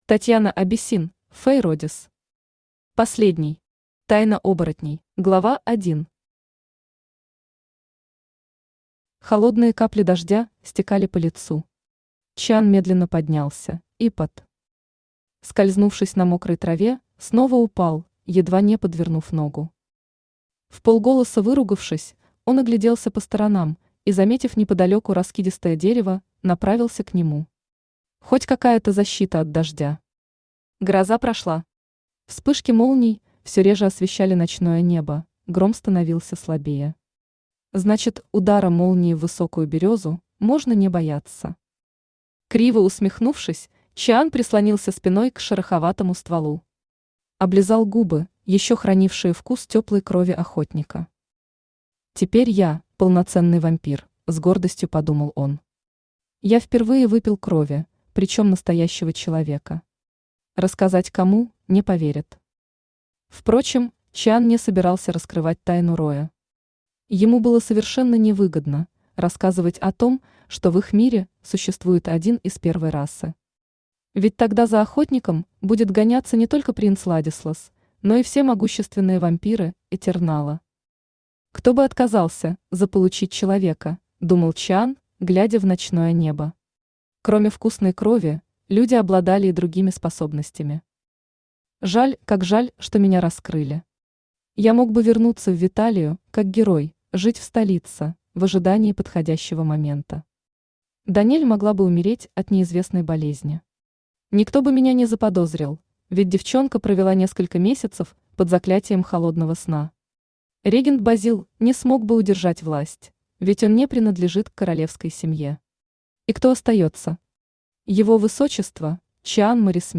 Аудиокнига Последний. Тайна оборотней | Библиотека аудиокниг
Читает аудиокнигу Авточтец ЛитРес